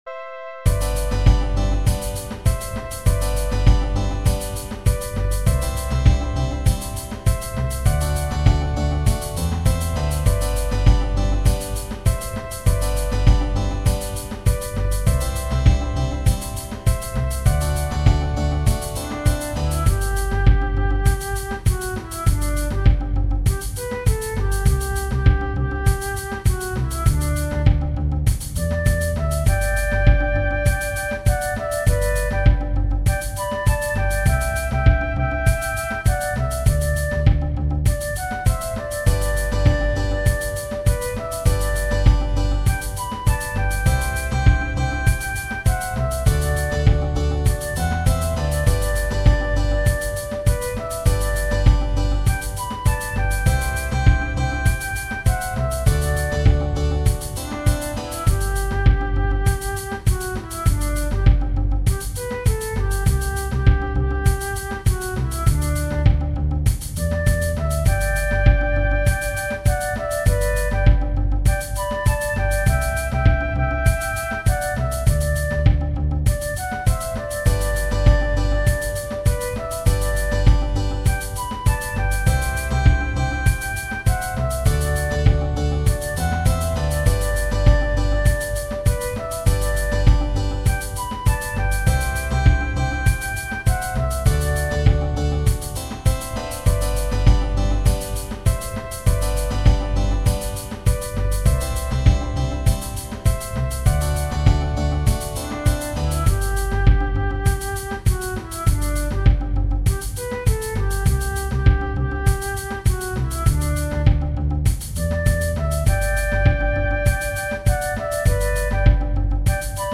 Genere: Folk
Questa interpretazione spiega meglio il carattere allegro del brano. Oggigiorno, la canzone viene ancora cantata in molte comunità durante feste e matrimoni, nonché da molti cori in Sud Africa.